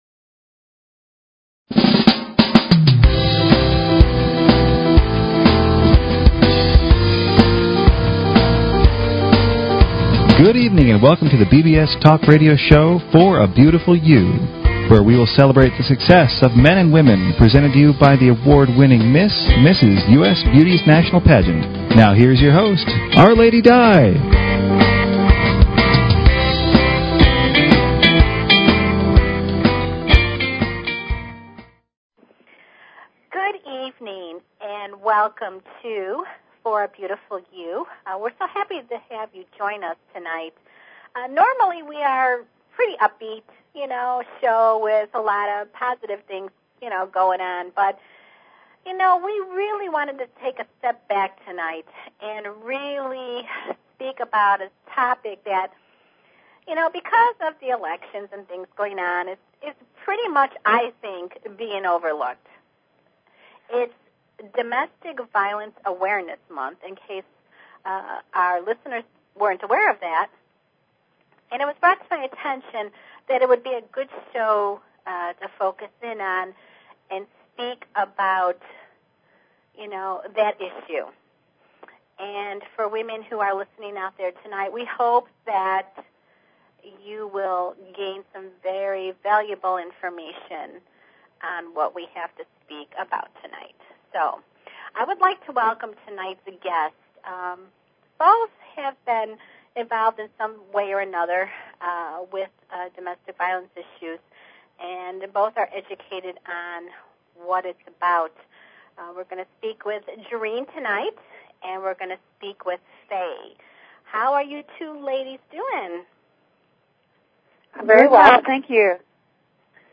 Talk Show Episode, Audio Podcast, For_A_Beautiful_You and Courtesy of BBS Radio on , show guests , about , categorized as
Presented by the award winning *Miss *Mrs. U.S. Beauties National Pageant Organization this live weekly one hour show gives a fresh perspective concerning the pageant, fashion, beauty and celebrity industry.